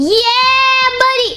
Worms speechbanks
Perfect.wav